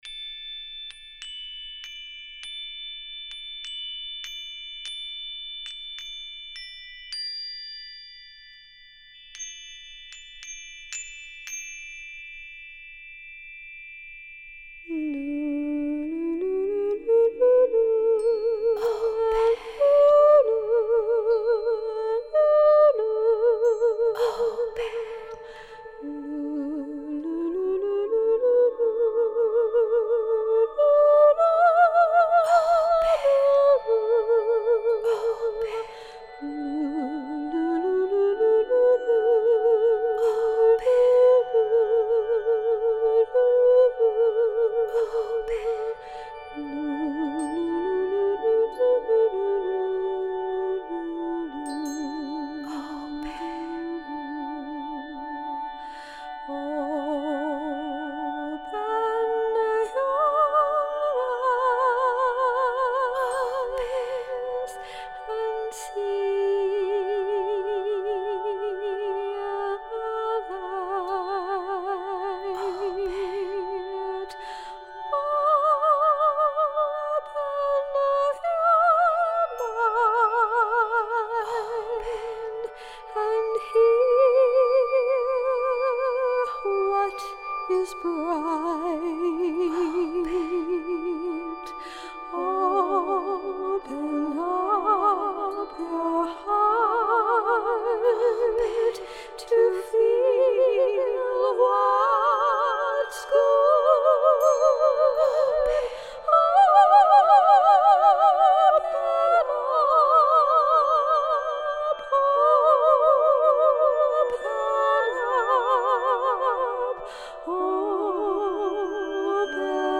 Vocals
Chimes
Tuning Forks on Quartz Points
Soundscapes